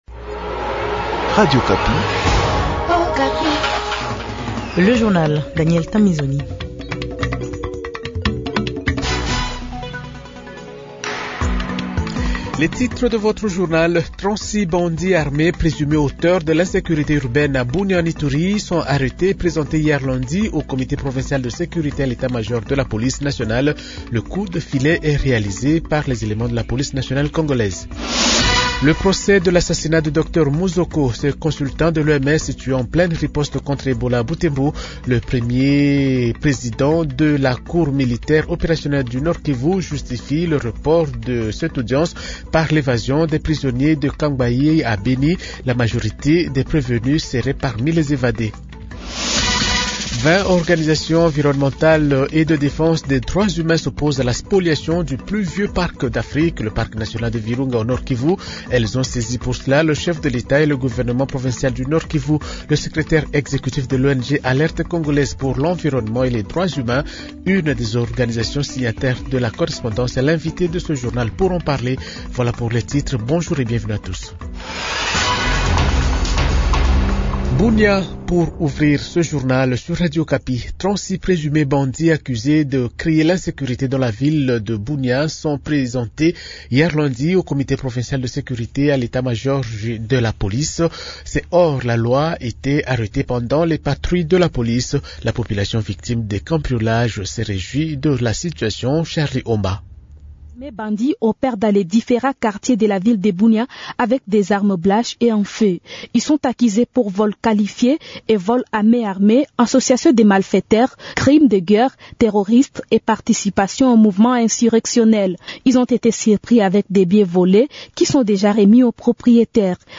Journal Francais Matin 8h00